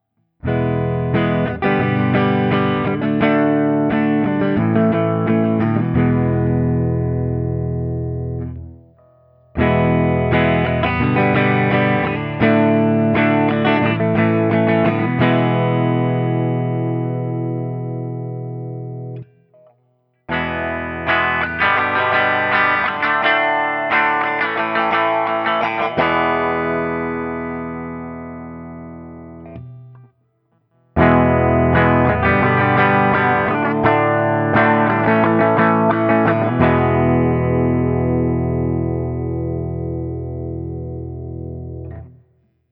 Open Chords #2
As usual, for these recordings I used my normal Axe-FX II XL+ setup through the QSC K12 speaker recorded direct into my Macbook Pro using Audacity.
For each recording I cycle through the neck pickup, both pickups, both pickups with phase reversed on the neck, and finally the bridge pickup.
Guild-Nightbird-I-ODS100-Open2.wav